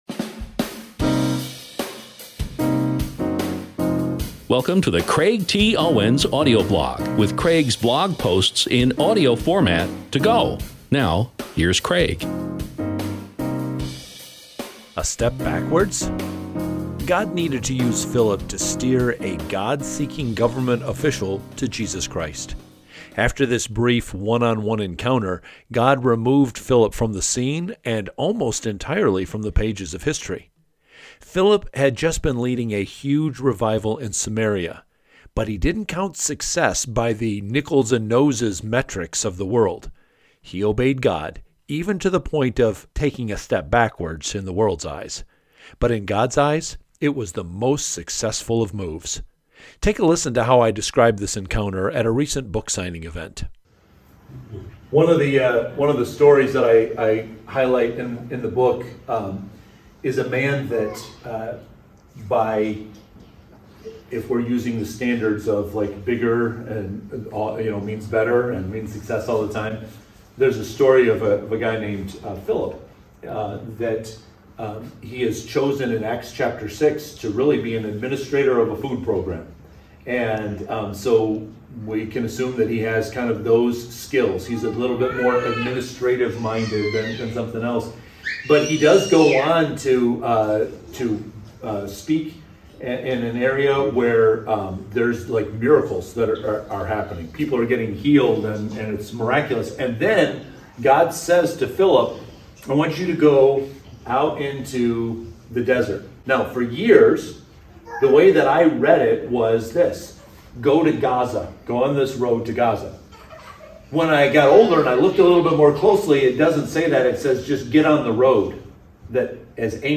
I’ll be sharing more clips from this book signing event soon, so please stay tuned.